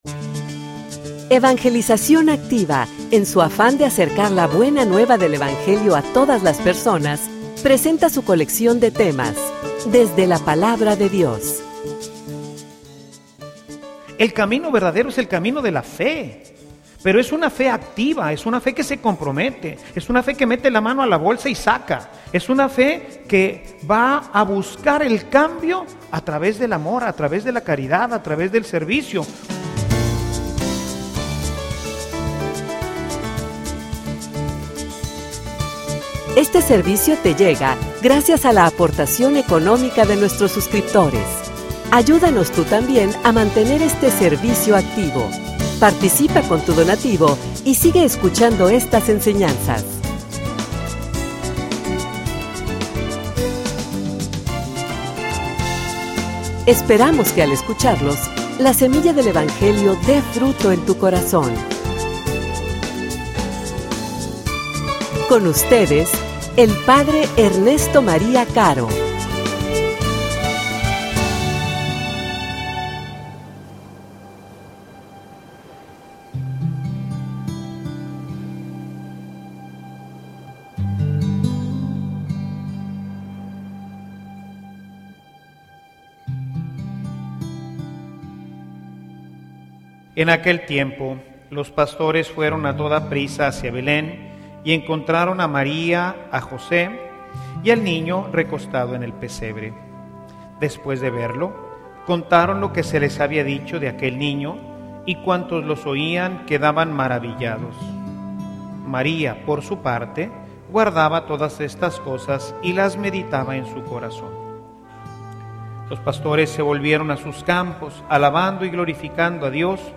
homilia_Una_fe_que_transforma.mp3